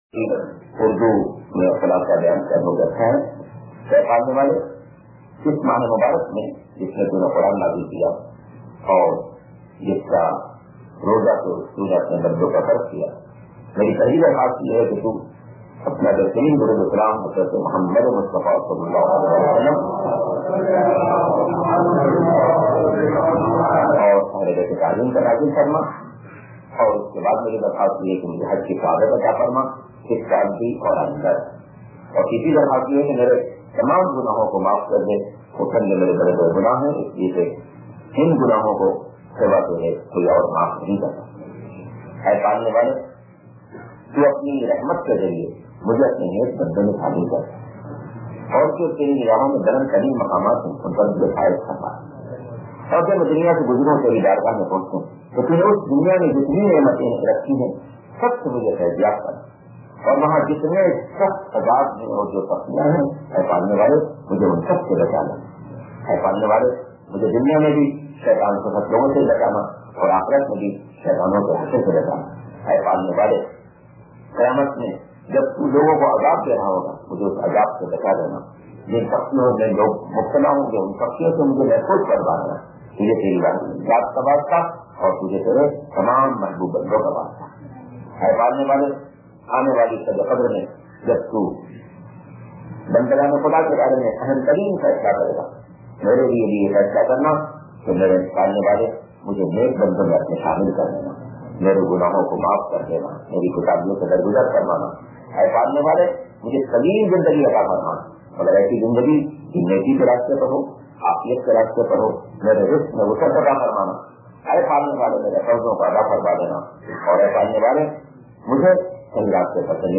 03_درس رمضان 2001